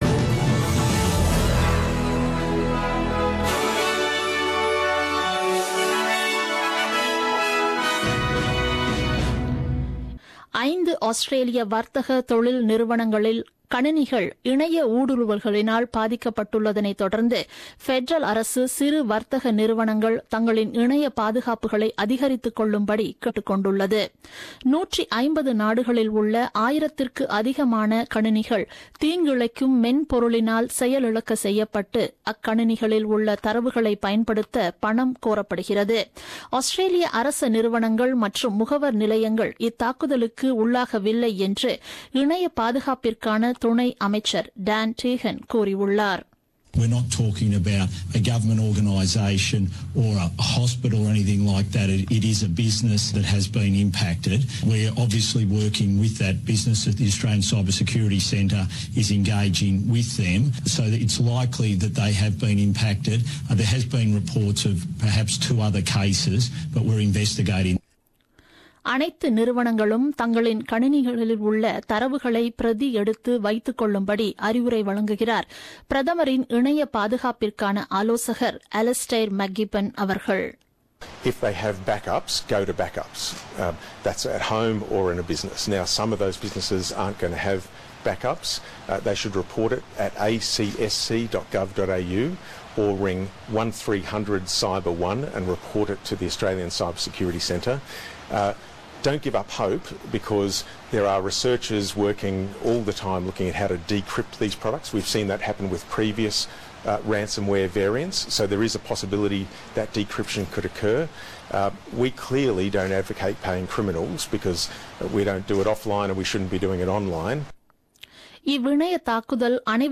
The news bulletin broadcasted on 15th May 2017 at 8pm.